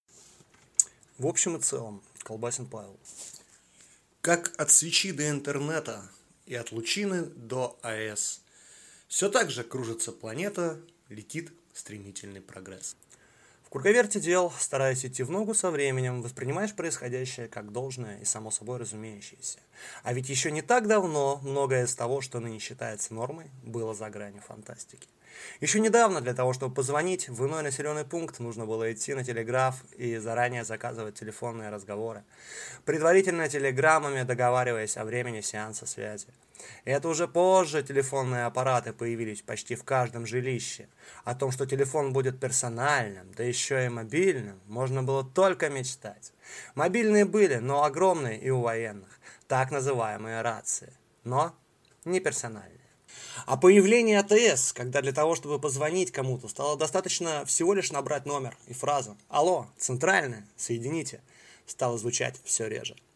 Aудиокнига В общем и целом